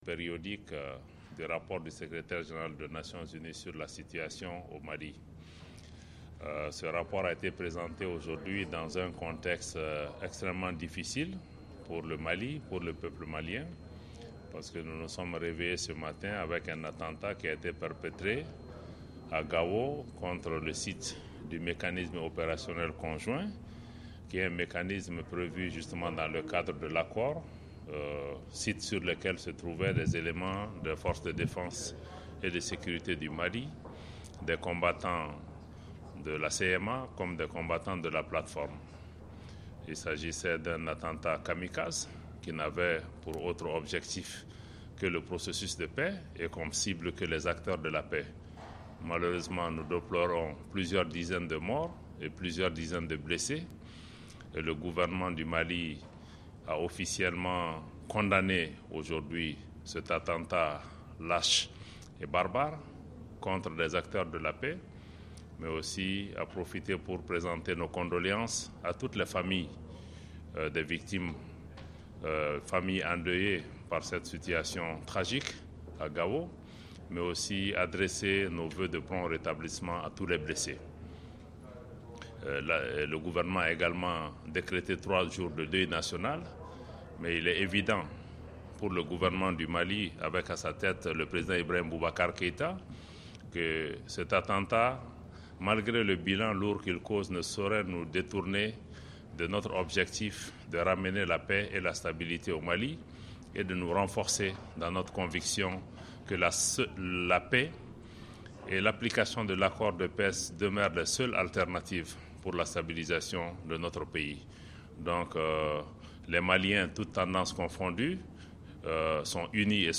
Au siège de l'ONU, le ministre des Affaires étrangères s'est exprimé sur la situation de l'accord de paix.
Abdoulaye Diop parle de l'attentat-suicide de Gao au siège de l'ONU